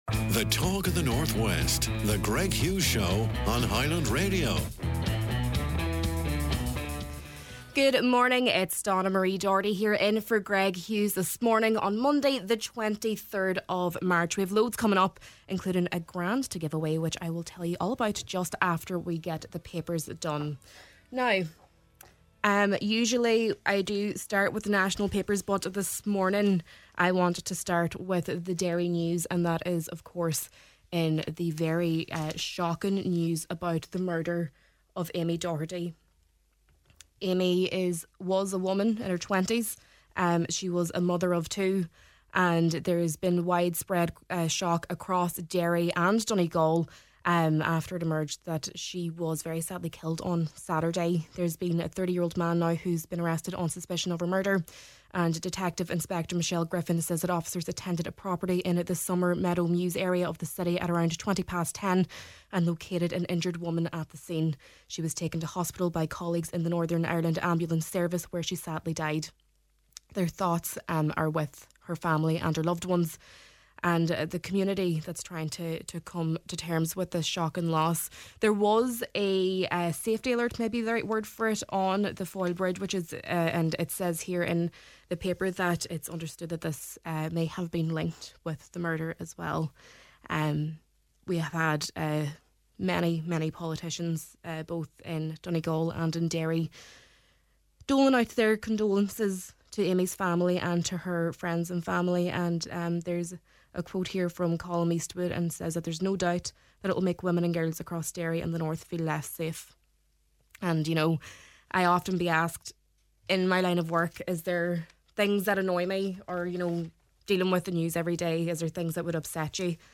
Government & Funding: Minister Dara Calleary discusses his visit to Donegal, focusing on the rollout of funding for the Gaeltacht and Ulster-Scots communities, and fuel supports for the region.